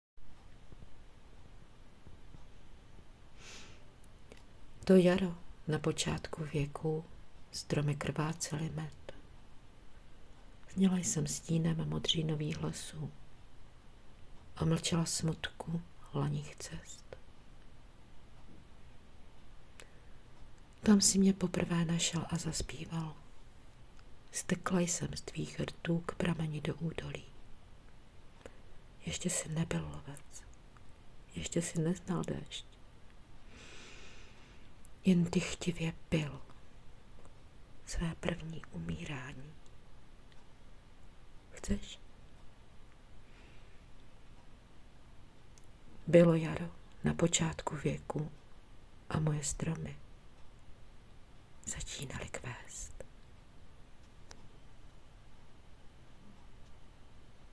Příjemné zas slyšet Tvůj nádherný hlas... *
Nahrávka je znělá a foneticky skvěle zpracovaná.